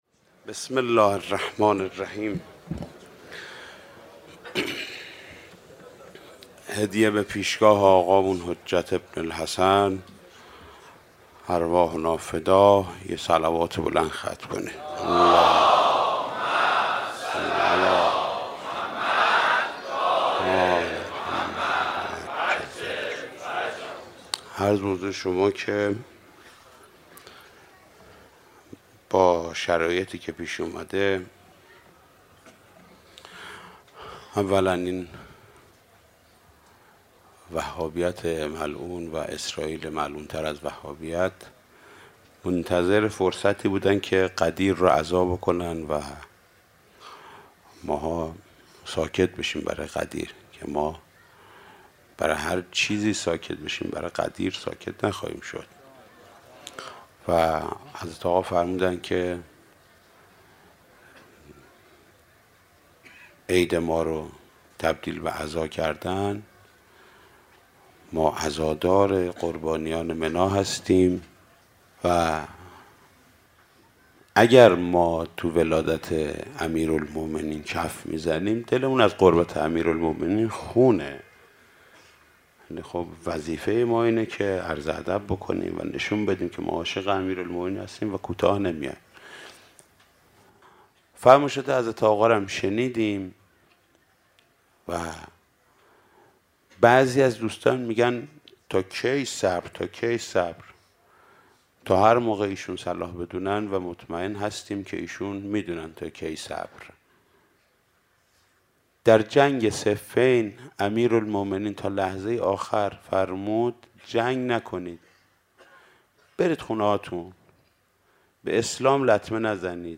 بخش اول - صحبت های پیرامون فاجعه دردناک منا - حاج محمود کریمی